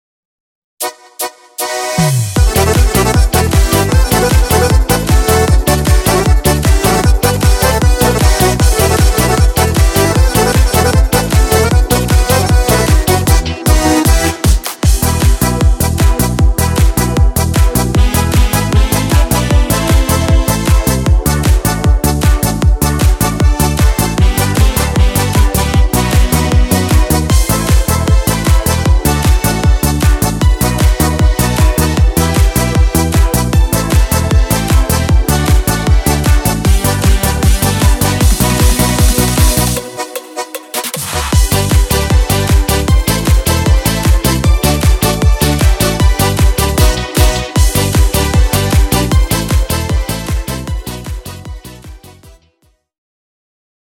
Disco Polo